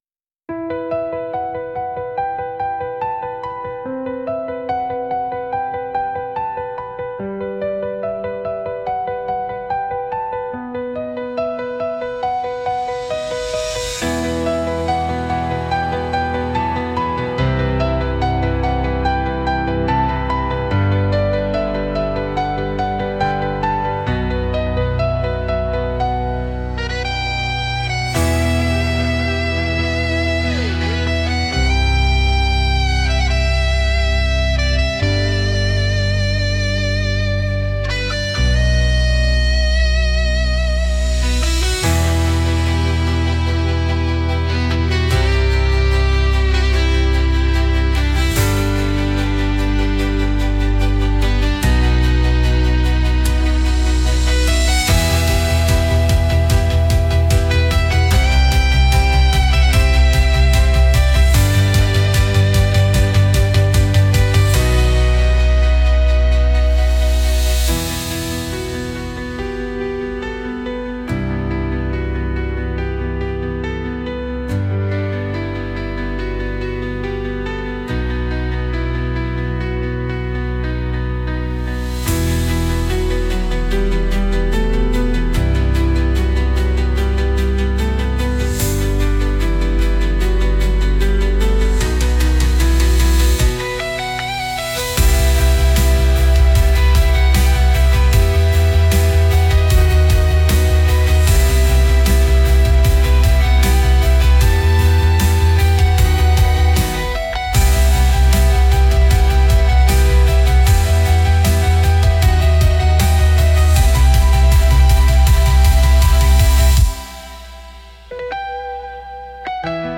Genre: Emotional Mood: Melodic Editor's Choice